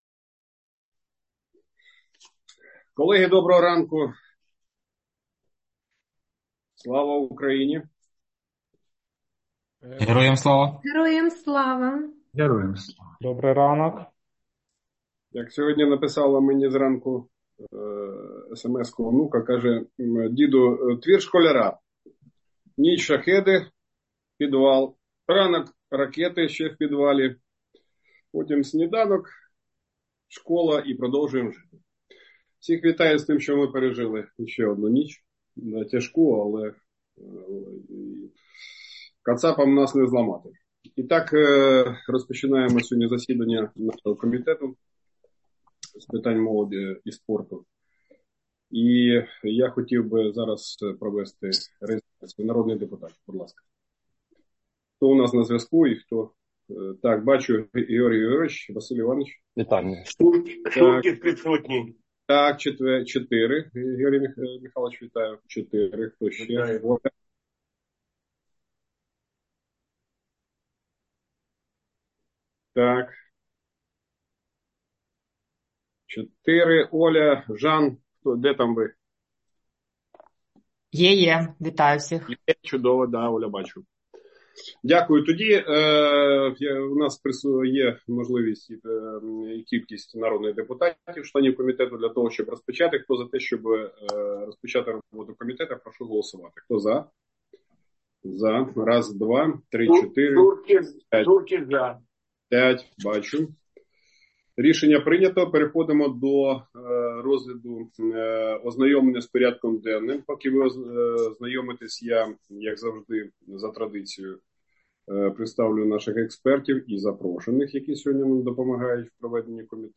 Аудіозаписи засідання Комітету у листопаді 2024 року
Назва файлу - Аудіозапис засідання 28.11.2024